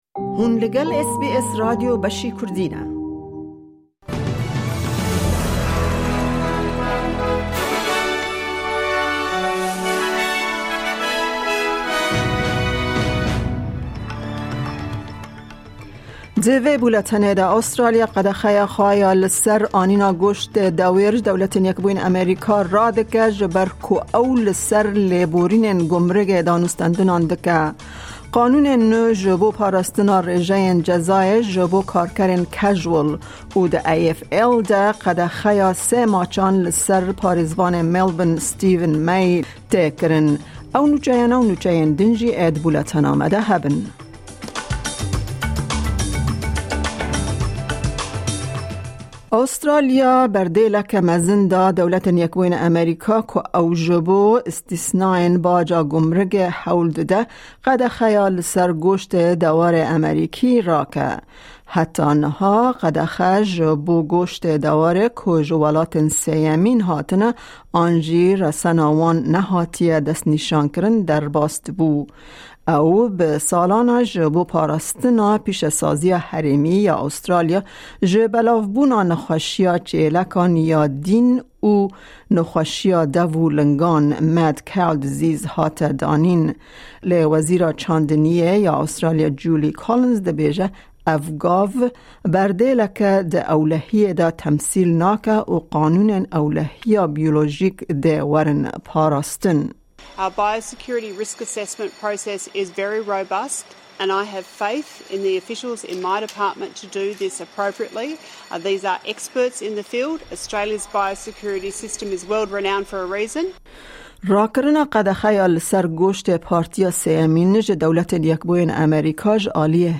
Ew nûçeyana û nûçeyên din di bûlentenê de hene.